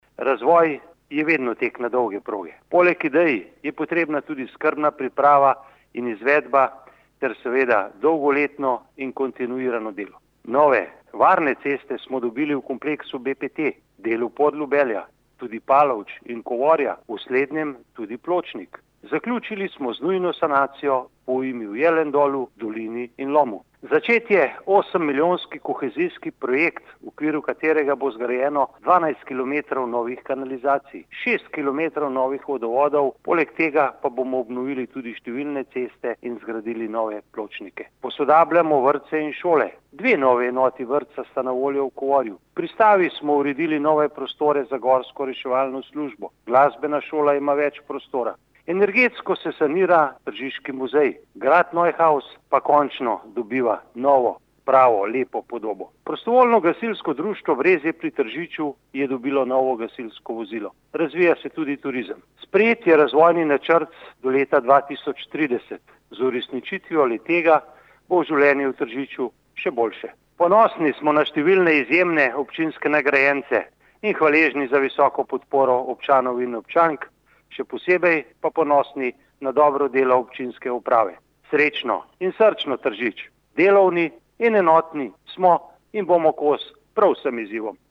izjava_mag.borutsajoviczupanobcinetrzic_novoleto.mp3 (2,1MB)